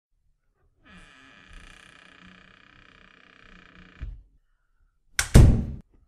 جلوه های صوتی
دانلود صدای جیر جیر و بستن طولانی در از ساعد نیوز با لینک مستقیم و کیفیت بالا
برچسب: دانلود آهنگ های افکت صوتی اشیاء دانلود آلبوم صدای بستن در از افکت صوتی اشیاء